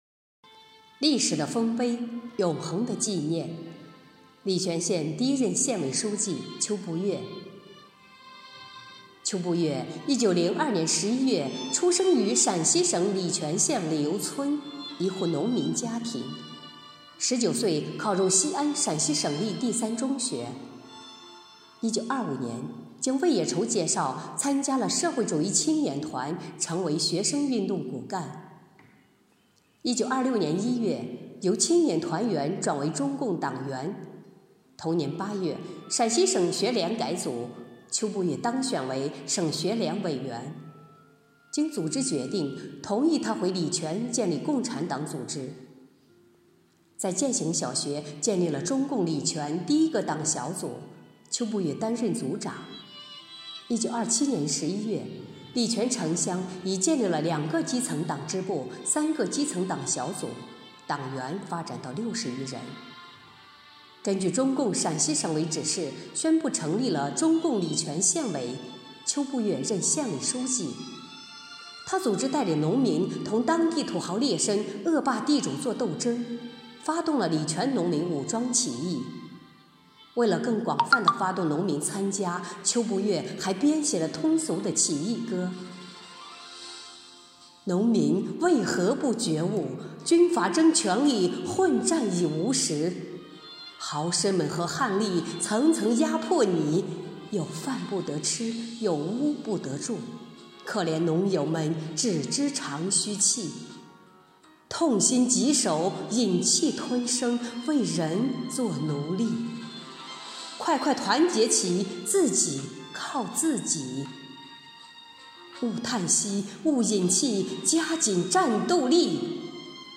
【红色档案诵读展播】历史的丰碑 永恒的纪念——礼泉县第一任县委书记秋步月